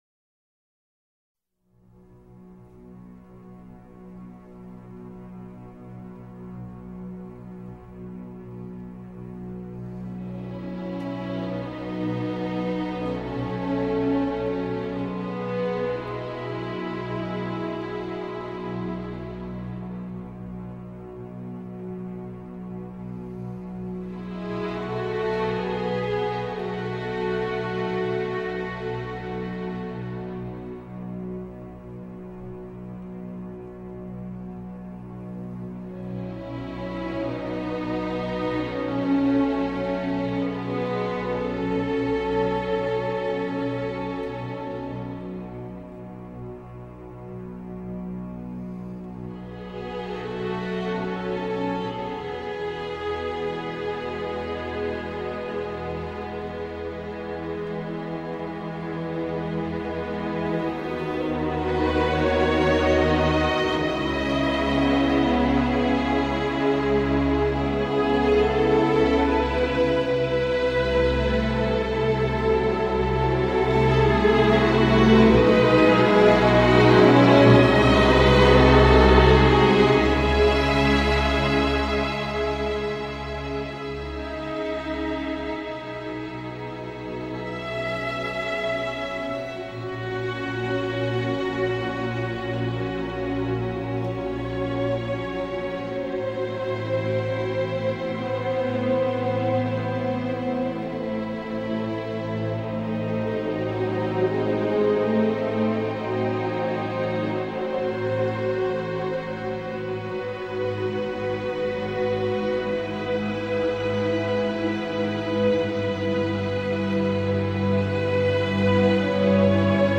Elle crie presque et gagne ainsi une puissance unique.